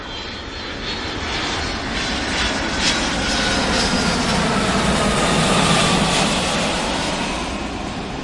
aeroplane.mp3